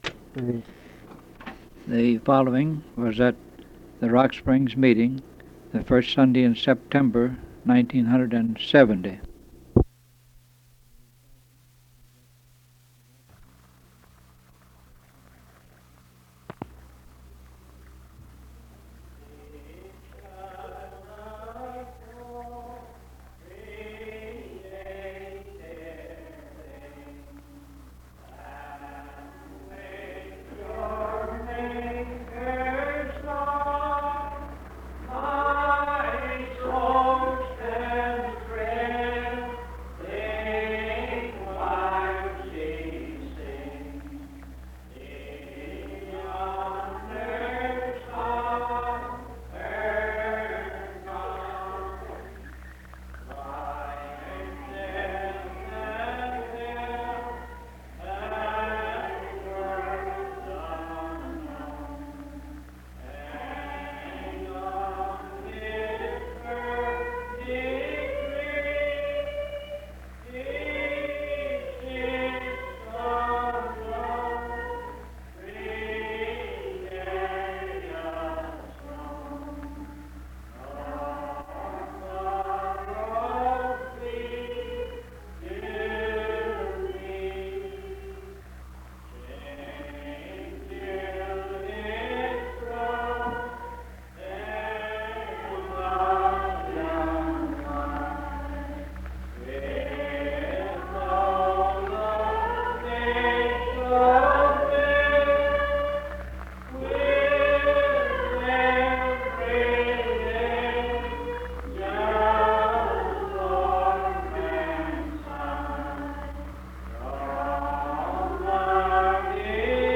General Preaching